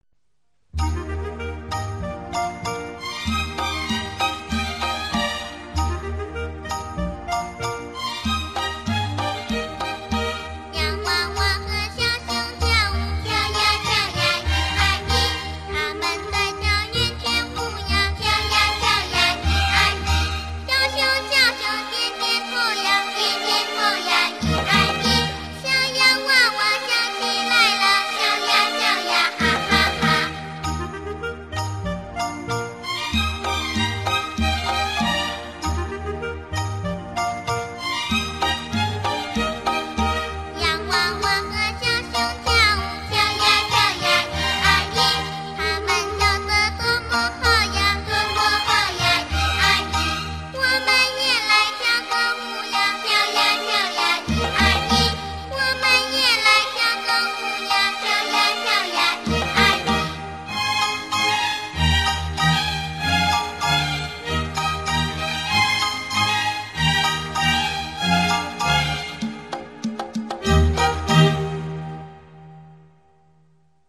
活动背景音乐——跳圆圈